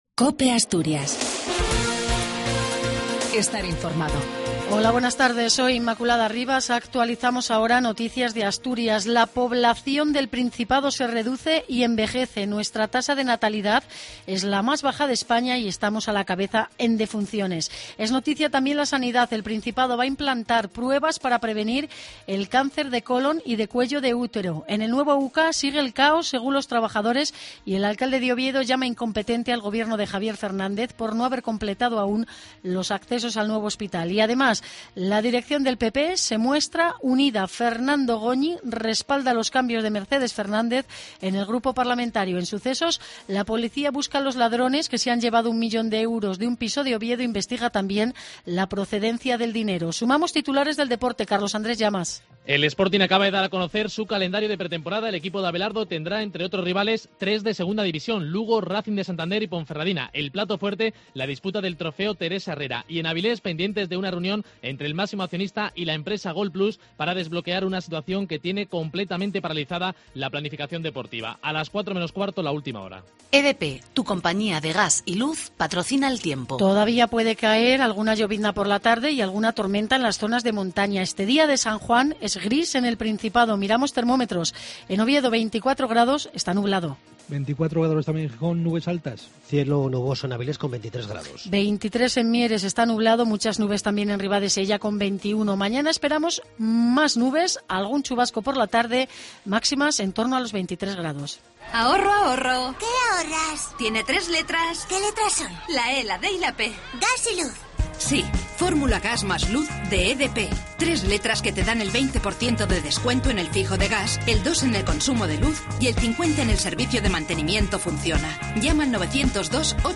AUDIO: LAS NOTICIAS DE ASTURIAS Y OVIEDO AL MEDIODIA.